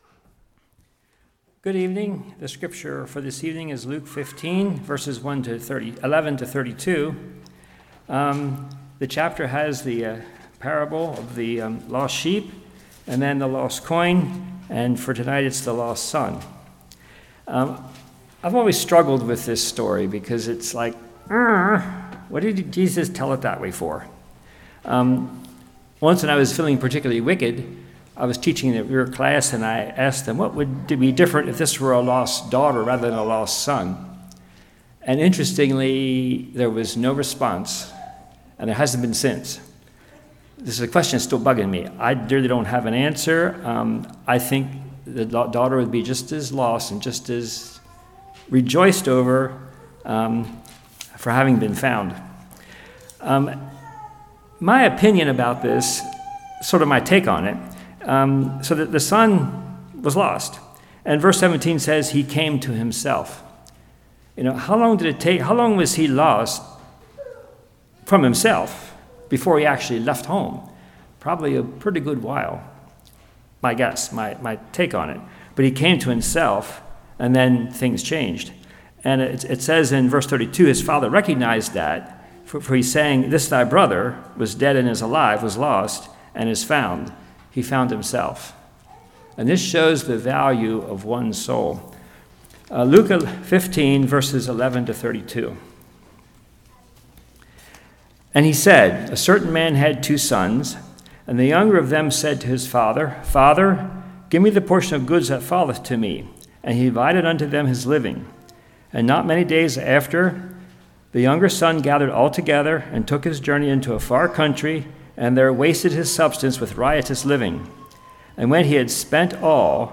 Luke 15:11-32 Service Type: Evening That which is lost must be found.